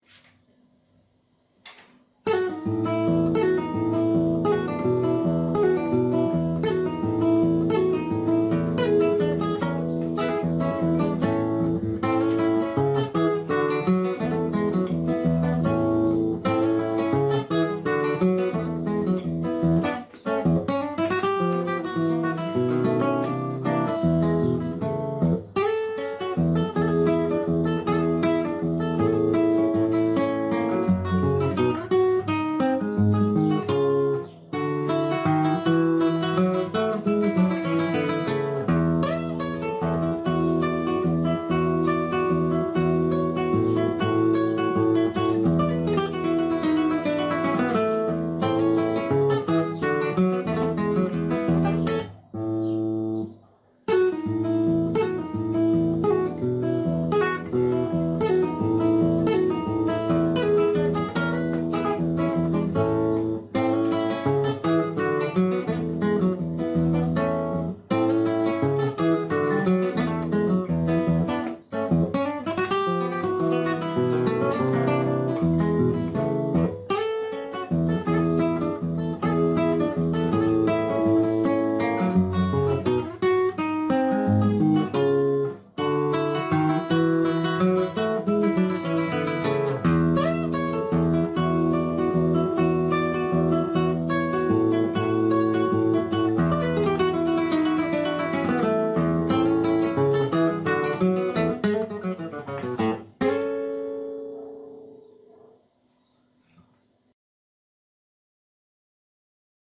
Profesor / Solista Instrumental